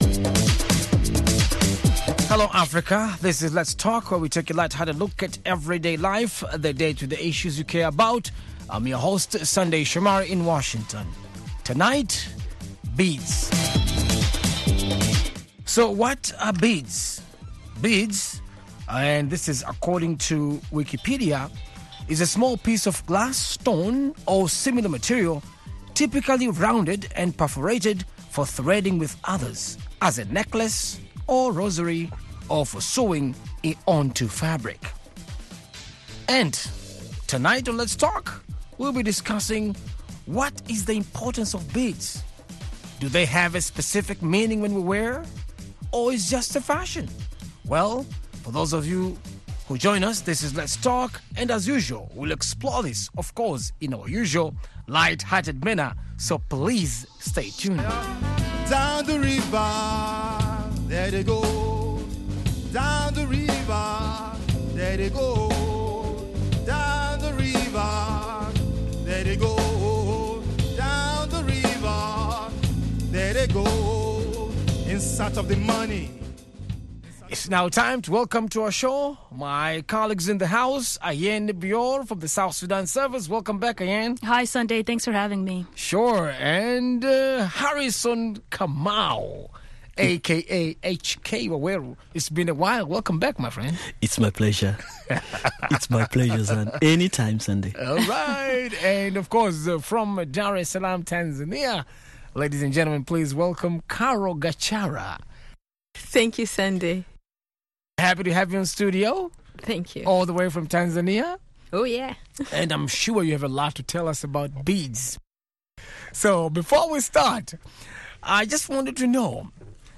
Let's Talk is an interactive discussion program about lifestyle issues.